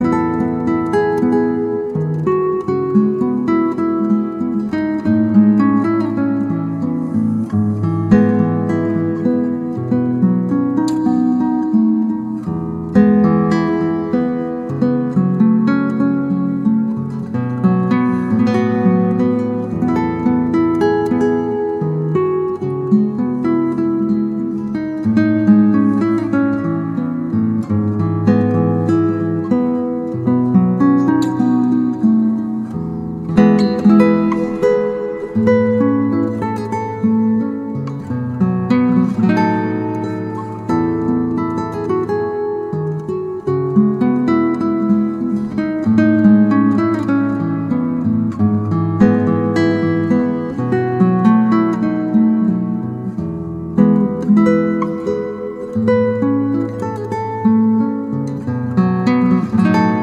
Instrumentalversionen beliebter Lobpreislieder
• Sachgebiet: Praise & Worship